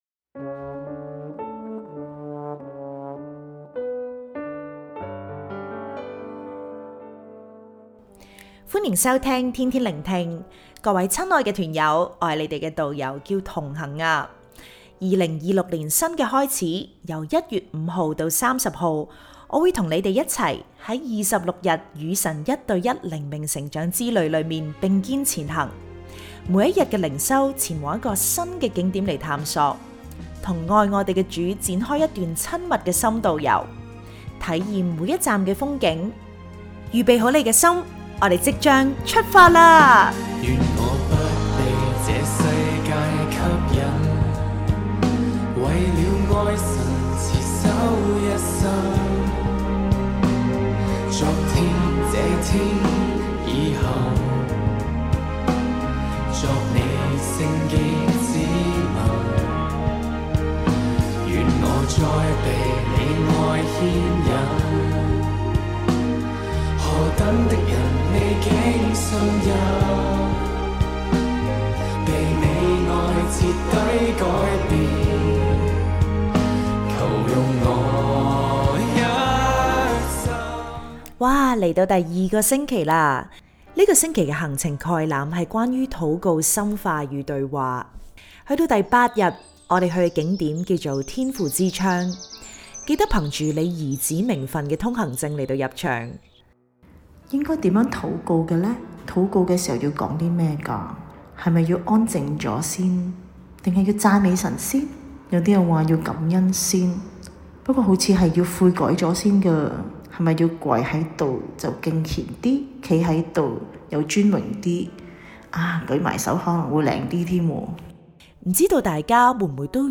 🎶靈修詩歌：《我是祢愛子》玻璃海樂團